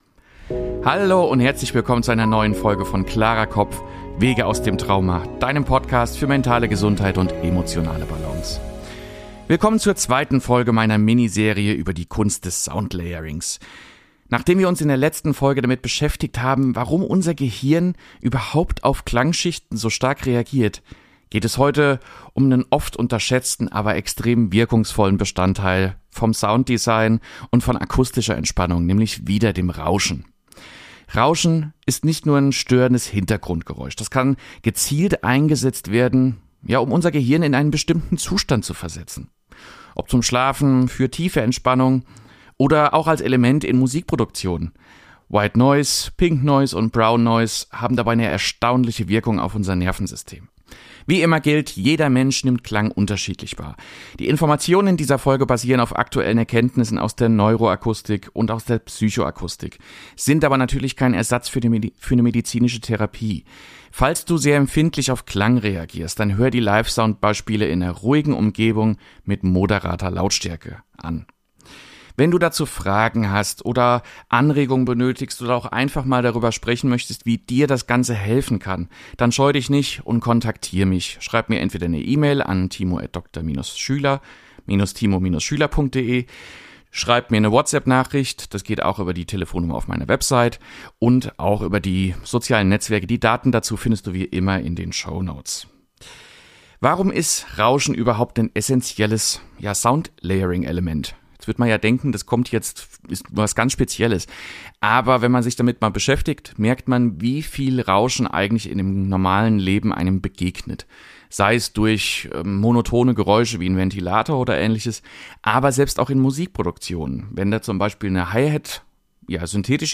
Mit praktischen Soundbeispielen.